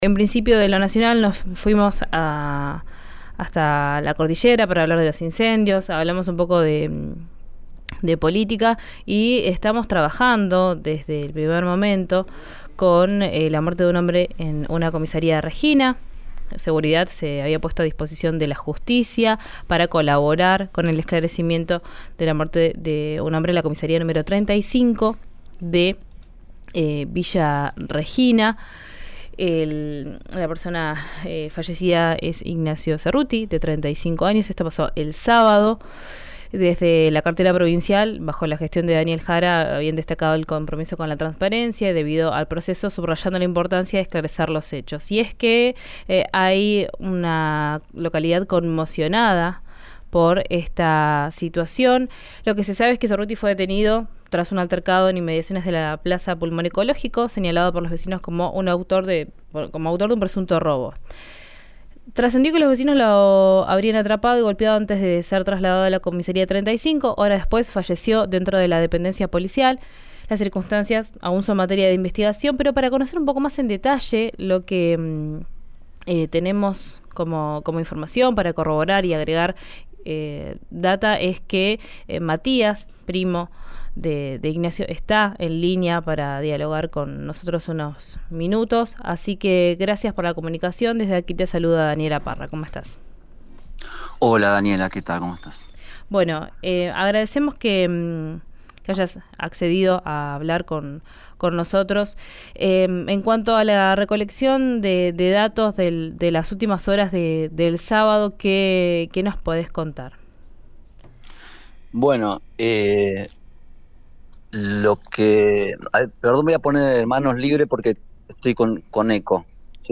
Desde el entorno del hombre se expresaron con RÍO NEGRO RADIO: apuntaron contra la Policía y hubo críticas hacia Fiscalía.